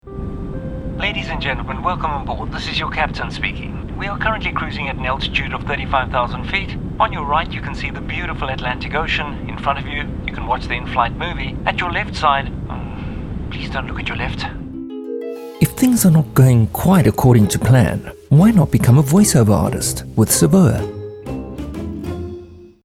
balanced, energetic, neutral, straightforward, unaccented
My demo reels